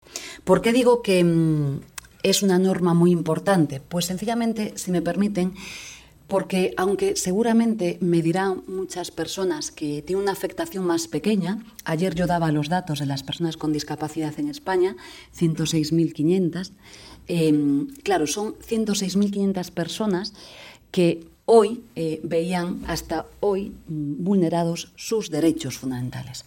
Yolanda Díaz enel acto de SERVIMEDIALa vicepresidenta segunda del Gobierno y ministra de Trabajo y Economía Social, Yolanda Díaz, destacó el 6 de marzo que la reforma del despido por incapacidad derivada de una discapacidad sobrevenida, que inicia ya su procedimiento, se produce porque “nuestro país tiene una deuda con las personas con discapacidad que se debe corregir", dijo formato MP3 audio(0,30 MB)Así lo manifestó en un diálogo organizado por Servimedia y la Unión de Profesionales y Trabajadores Autónomos (UPTA) bajo el título ‘Los retos y el futuro del trabajo autónomo’, celebrada en el marco del 35º aniversario de Servimedia.